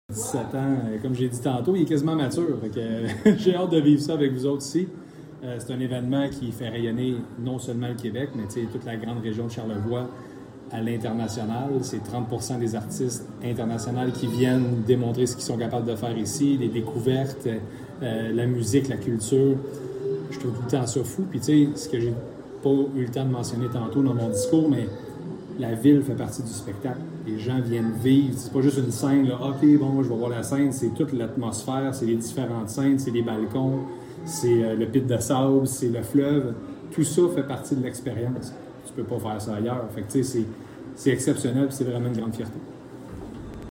Un commentaire de Gabriel Hardy député fédéral de Montmorency—Charlevoix.